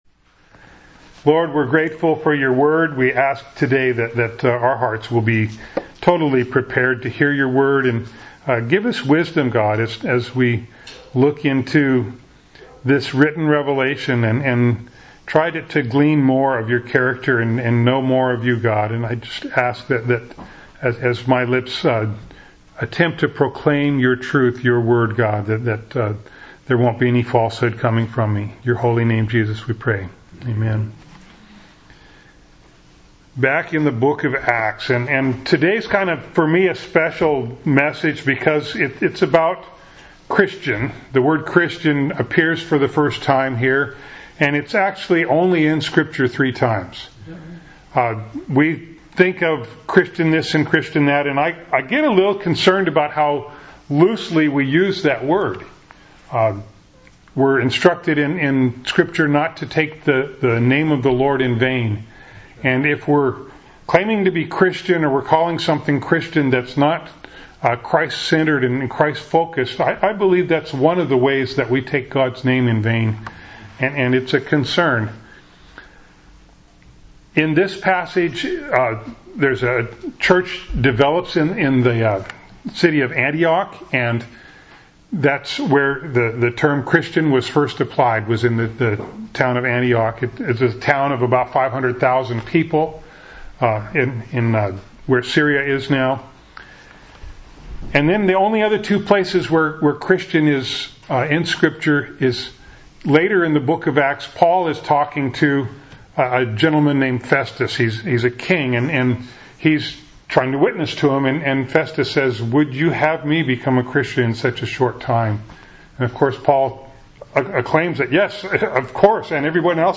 Acts 11:19-30 Service Type: Sunday Morning Bible Text